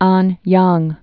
(änyäng)